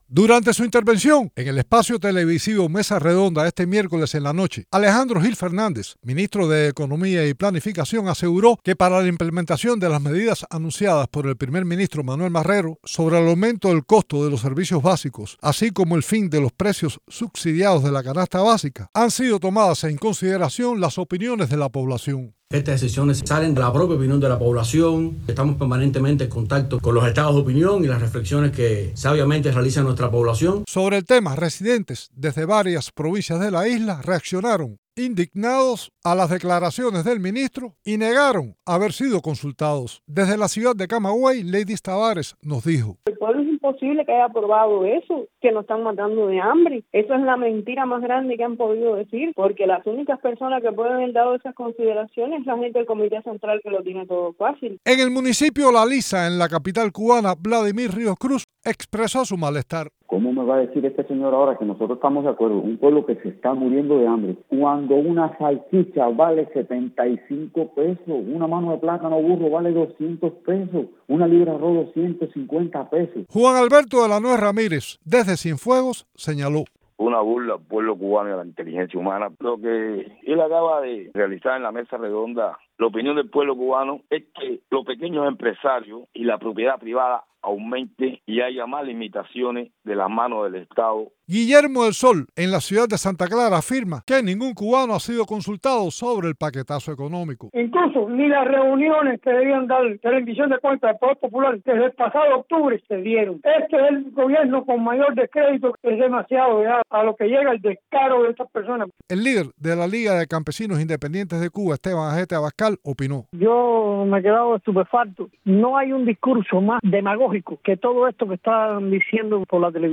Residentes desde varias provincias cubanas desmienten al ministro de economía y planificación.